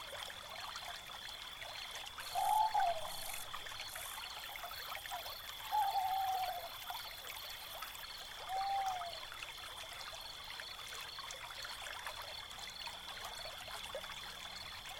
Ambiance La Forêt des Hiboux (Broadcast) – Le Studio JeeeP Prod
Bruits d’ambiance dans une forêt peuplée de hiboux.
Ambiance-Foret-des-hiboux.mp3